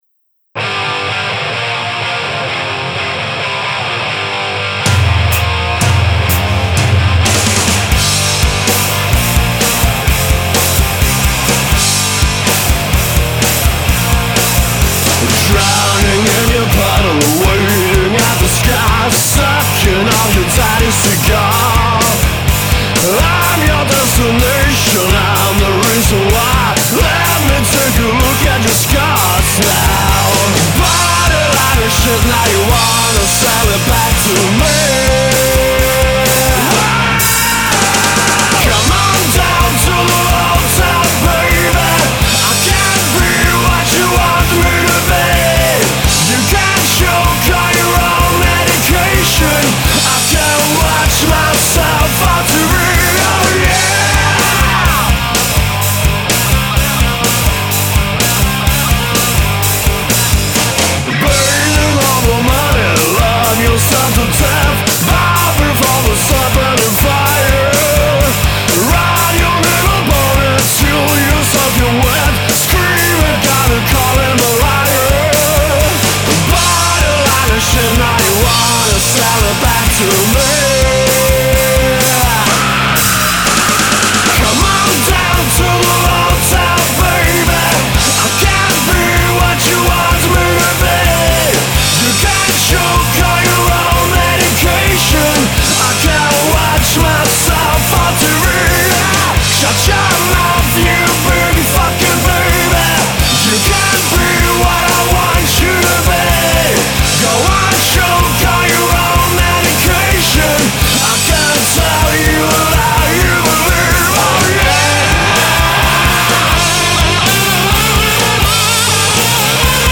har bildat ett coverband som bara spelar bra hårdrock